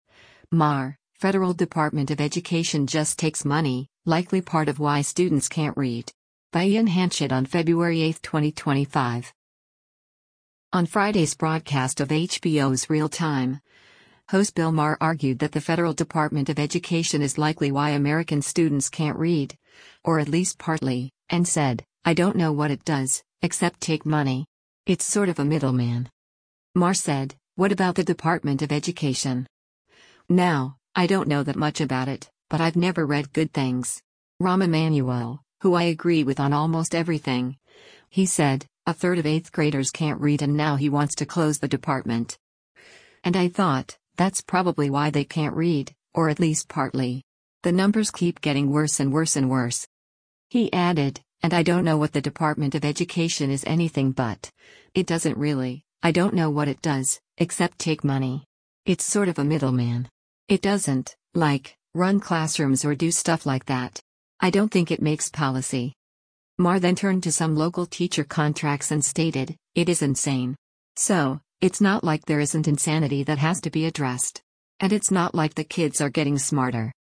On Friday’s broadcast of HBO’s “Real Time,” host Bill Maher argued that the federal Department of Education is likely why American students “can’t read, or at least partly.” And said, “I don’t know what it does, except take money.